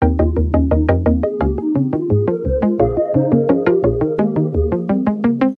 без слов
electronic